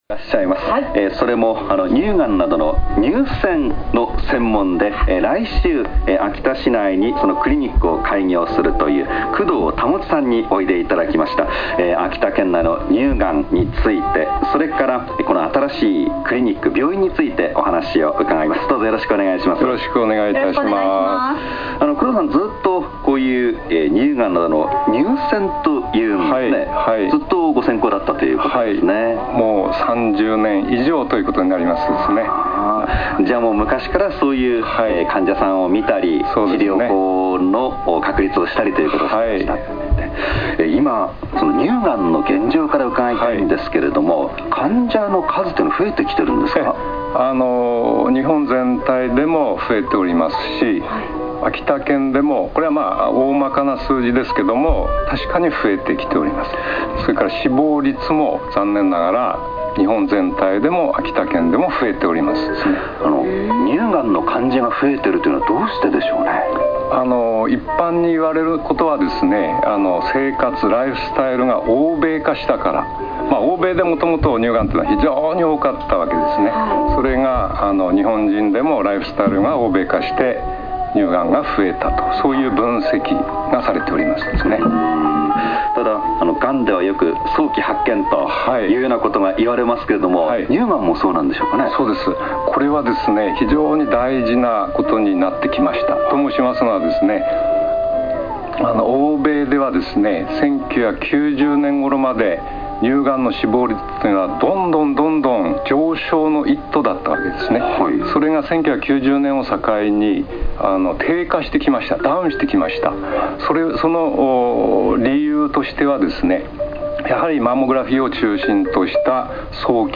ABS秋田放送『あさ採りワイド秋田便』でのインタビュー